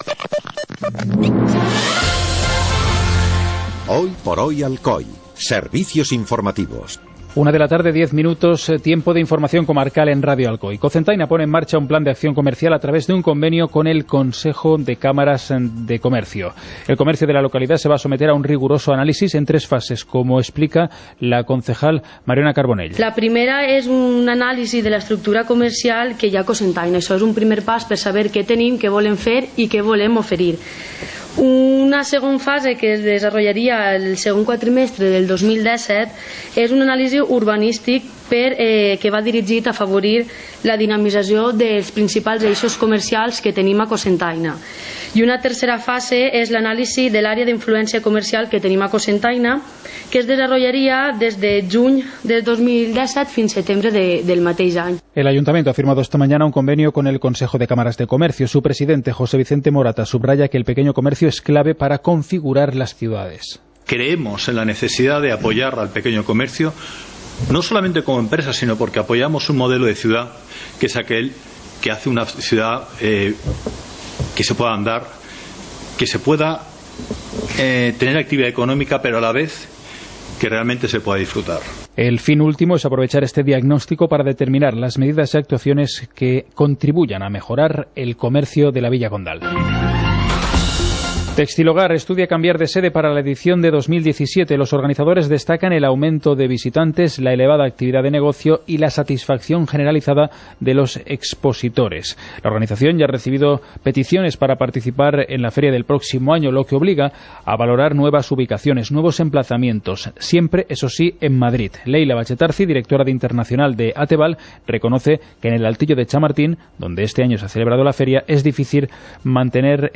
Informativo comarcal - lunes, 12 de septiembre de 2016